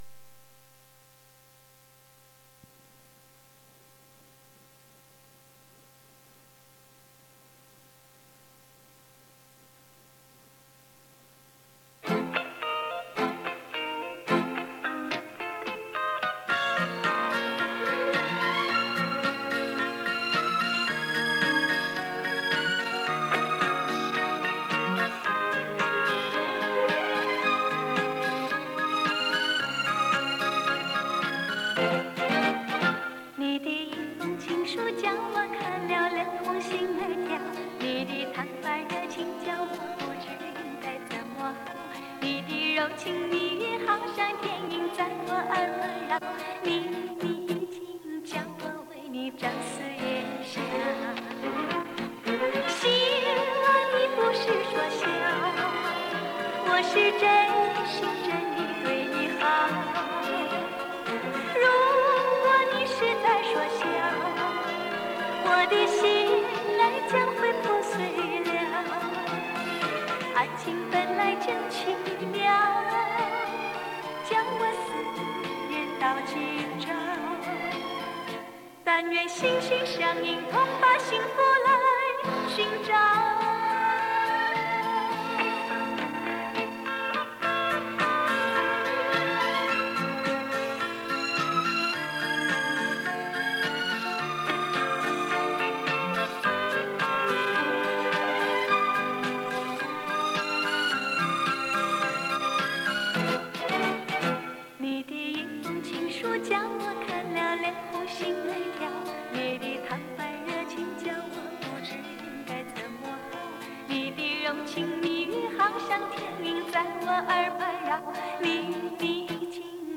磁带数字化：2022-07-29